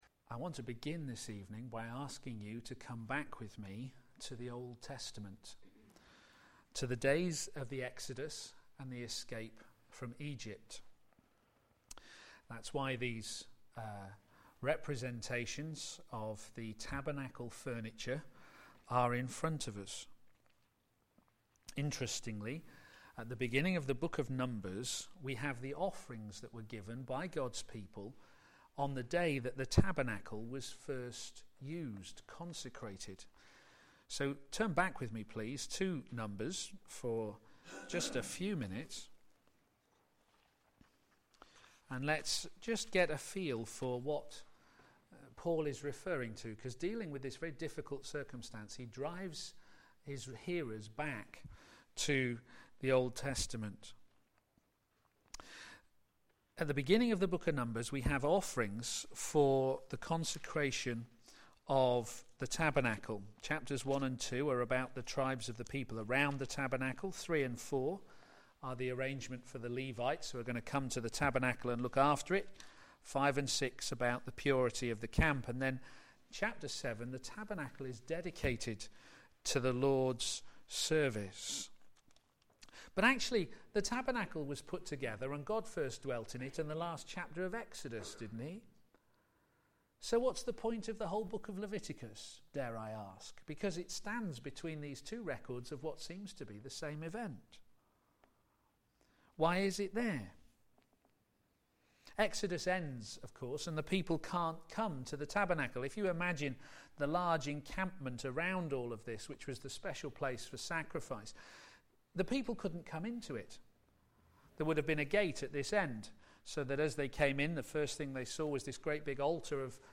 Media Library Media for p.m. Service on Sun 13th Oct 2013 18:30 Speaker
Working Together to Advance the Gospel Theme: Immorality! It's consequences and the church's responsibility Sermon